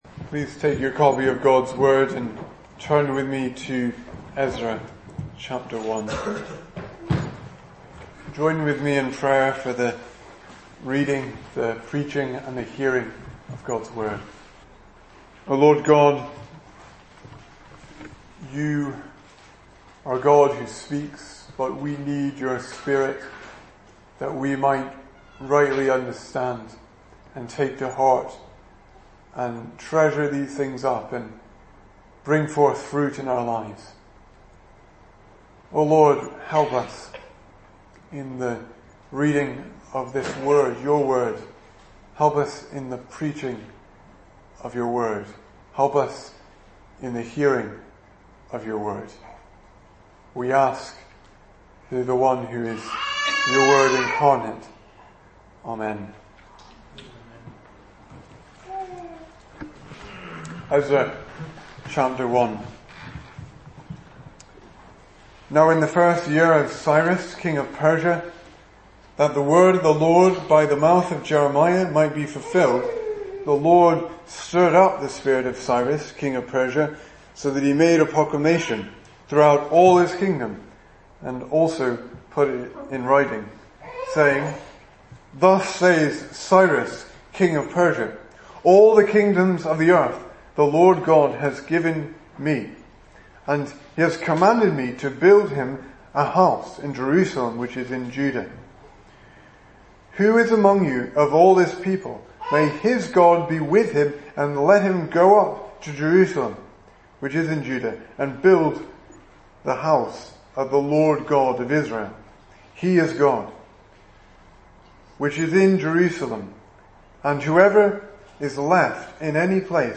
2018 Service Type: Sunday Evening Speaker